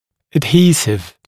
[əd’hiːsɪv][эд’хи:сив]адгезив, клеящее вещество